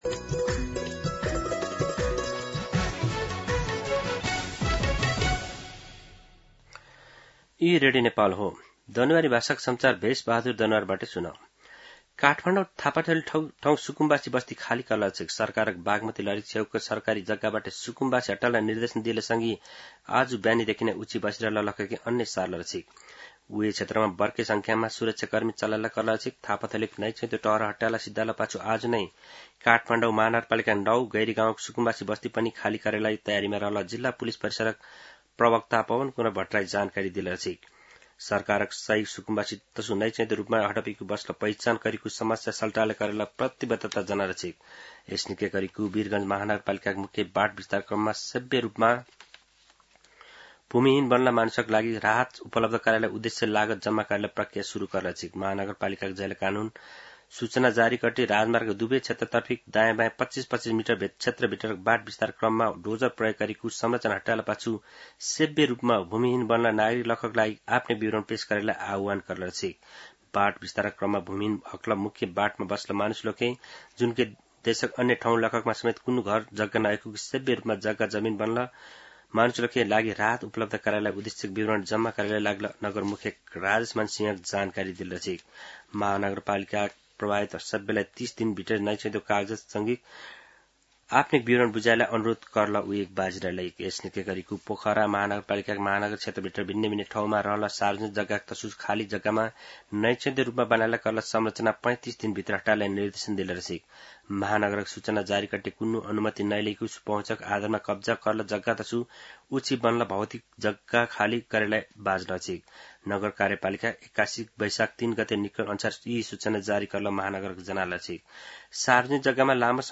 दनुवार भाषामा समाचार : १२ वैशाख , २०८३
Danuwar-News-12.mp3